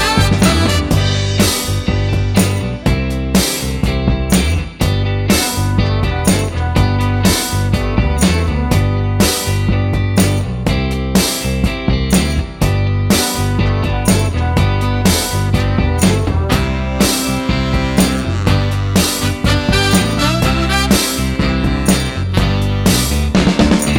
Two Semitones Down Soul / Motown 2:38 Buy £1.50